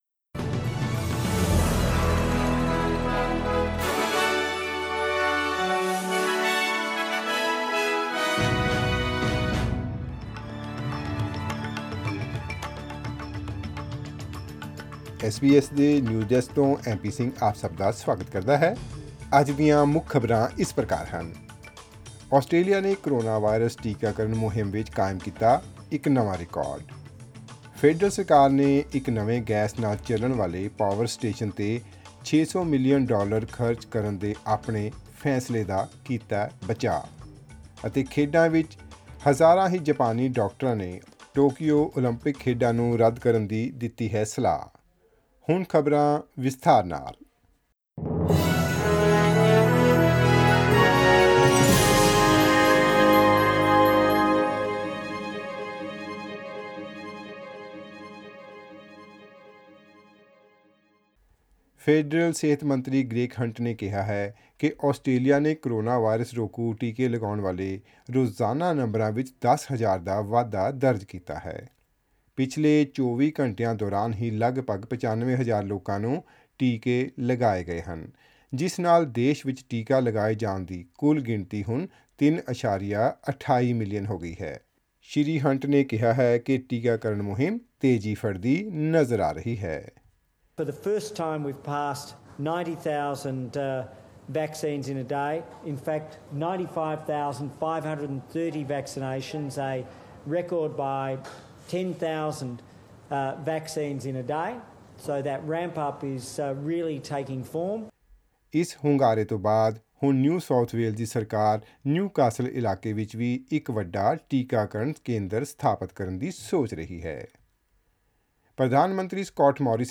Presenting the major national and international news stories of today; sports, currency exchange rates and the weather forecast for tomorrow. Click on the player at the top of the page to listen to the news bulletin in Punjabi.